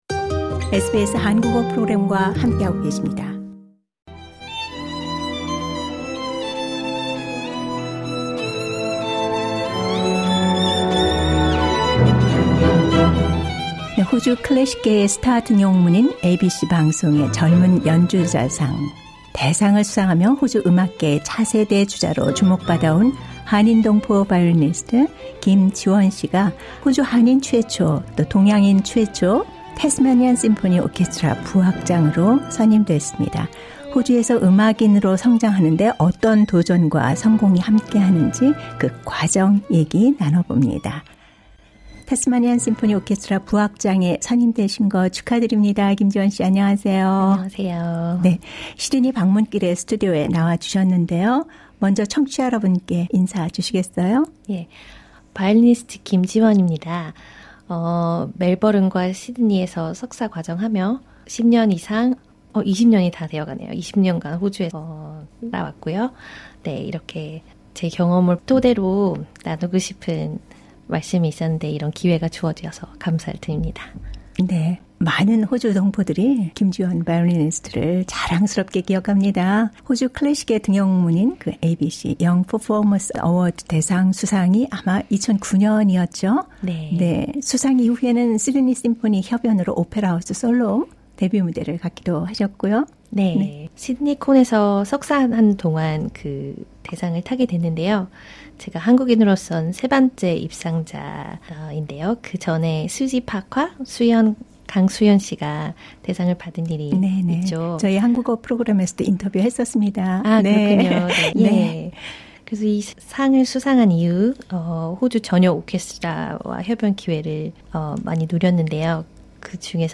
인터뷰: 멜버른 국제챔버콩쿠르 도전장 내민 한국의 '리수스 콰르텟'